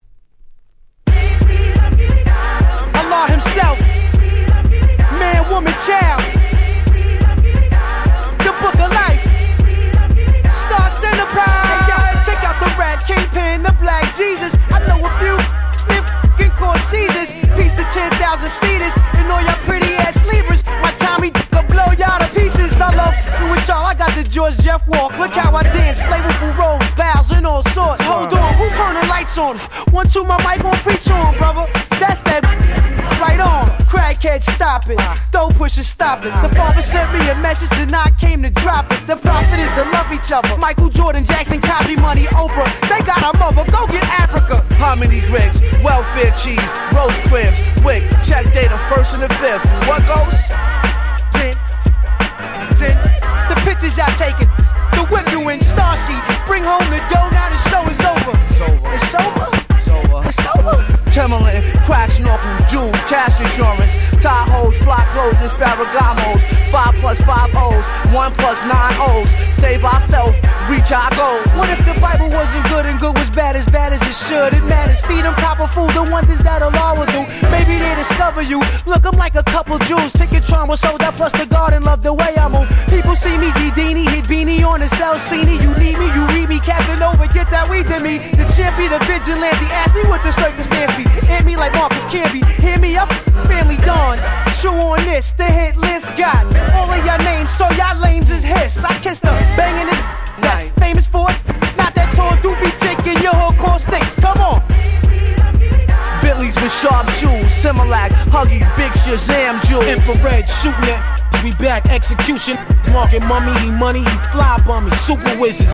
01' Smash Hit Hip Hop Album !!